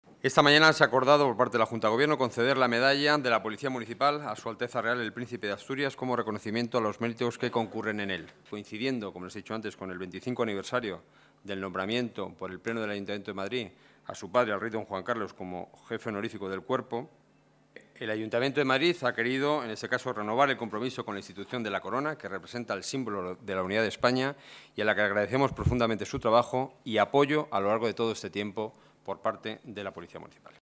Nueva ventana:Declaraciones del portavoz del Gobierno municipal, Enrique Núñez